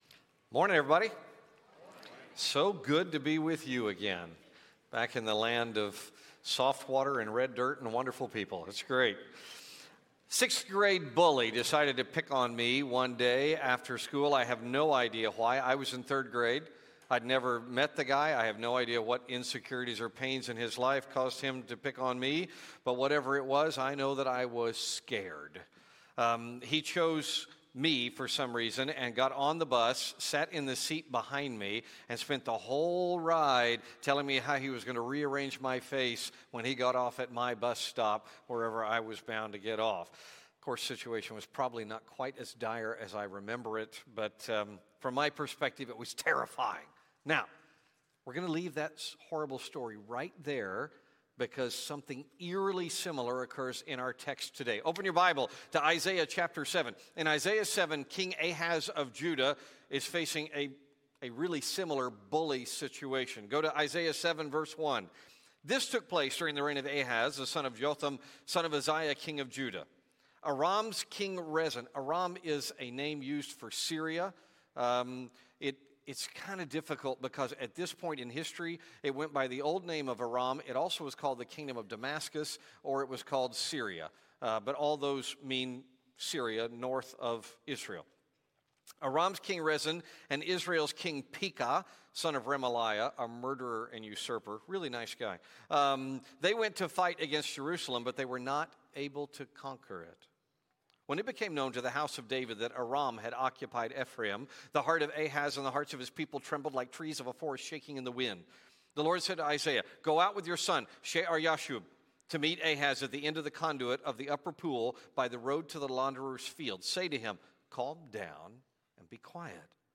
Isaiah 7 | South Spring Baptist Church Tyler TX